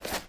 STEPS Dirt, Run 26.wav